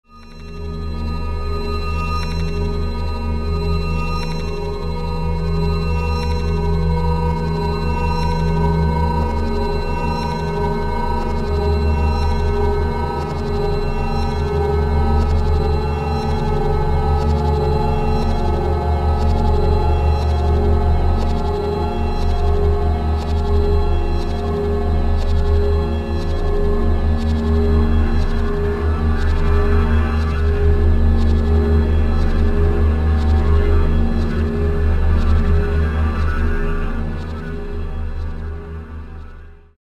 Compelling compositions, haunting and
mysterious